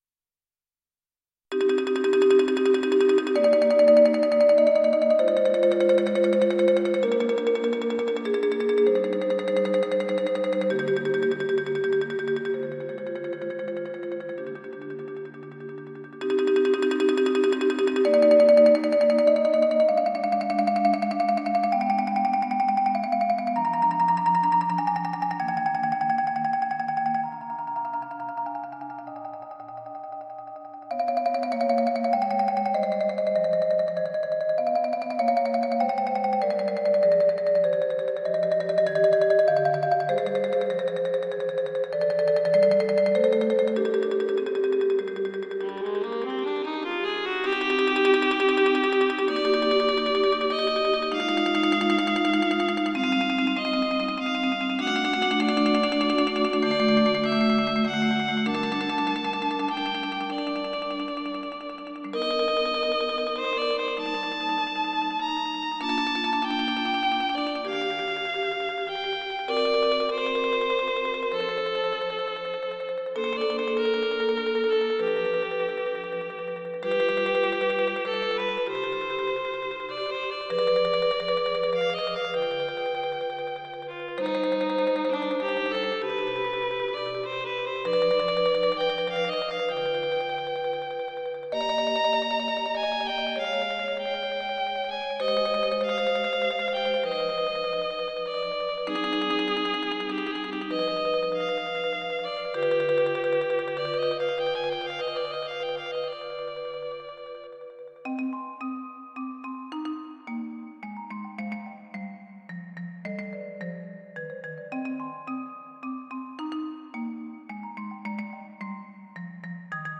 クラシック 　ＭＩＤＩ(65KB） 　YouTube